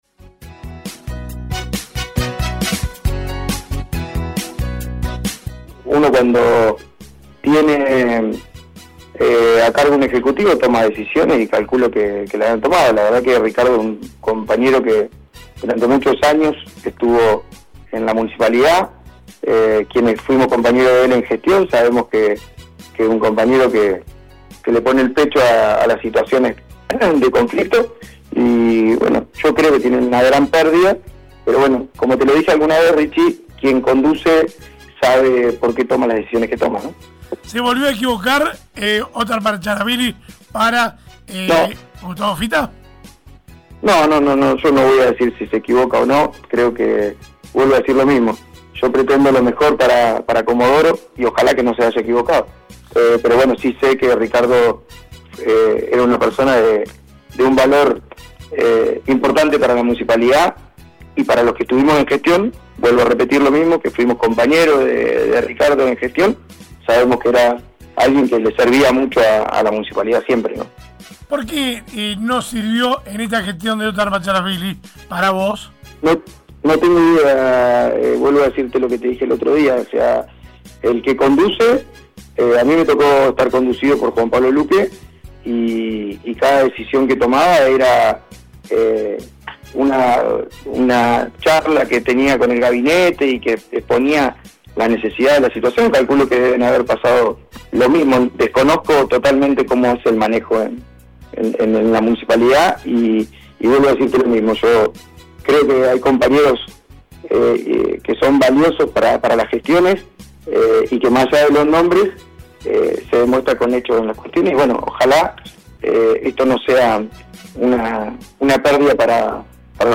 en diálogo con LA MAÑANA DE HOY